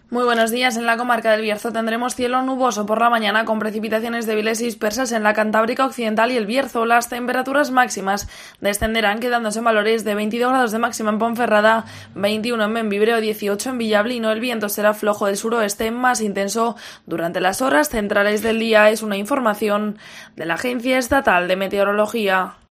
Previsión del tiempo Bierzo